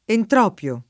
vai all'elenco alfabetico delle voci ingrandisci il carattere 100% rimpicciolisci il carattere stampa invia tramite posta elettronica codividi su Facebook entropio [ entr 0 p L o ] s. m. (med.); pl. ‑pi (raro, alla lat., ‑pii ) — anche, alla greca, entropion [ entr 0 p L on ]